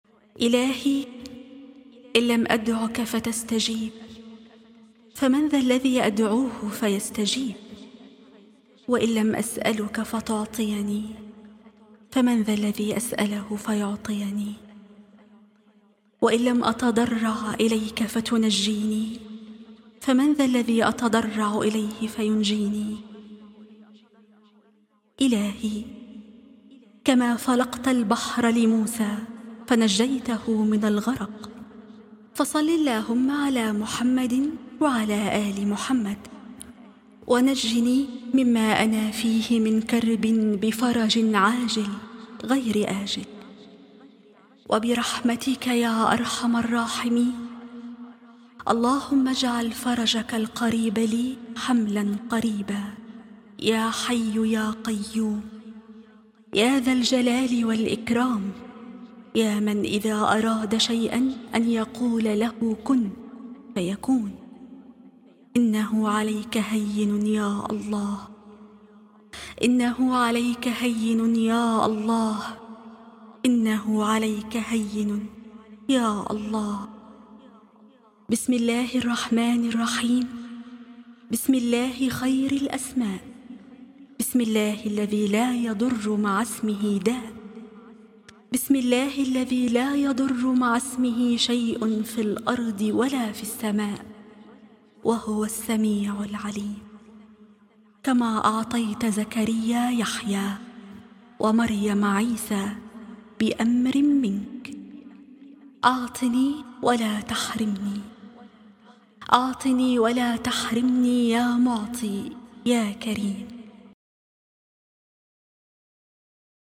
دعاء مؤثر مليء بالمناجاة والتوسل إلى الله تعالى، يعبر عن اليقين في الاستجابة والفرج القريب. يحتوي على التضرع بذكر أسماء الله الحسنى وطلب النجاة من الكربات، مع التوسل بالنبي محمد صلى الله عليه وآله وسلم.